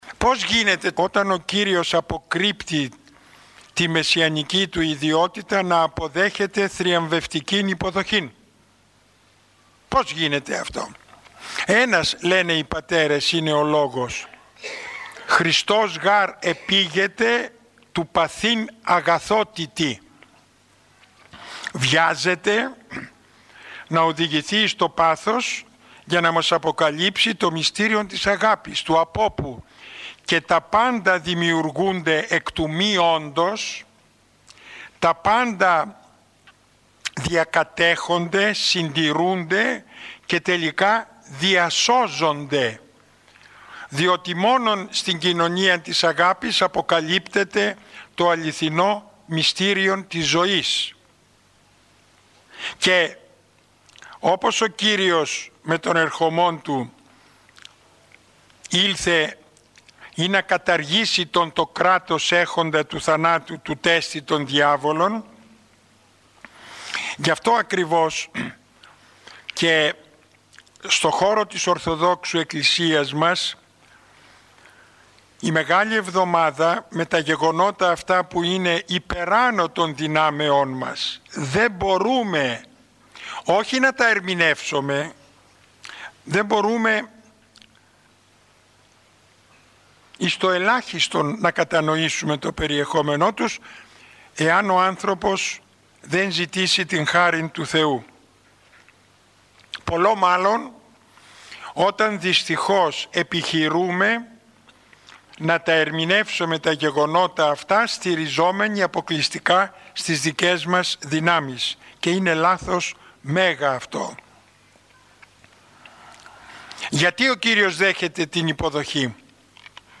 Η ομιλία αυτή “δόθηκε” στα πλαίσια του σεμιναρίου Ορθοδόξου πίστεως – του σεμιναρίου οικοδομής στην Ορθοδοξία. Το σεμινάριο αυτό διοργανώνεται στο πνευματικό κέντρο του Ιερού Ναού της Αγ. Παρασκευής (οδός Αποστόλου Παύλου 10), του ομωνύμου Δήμου της Αττικής.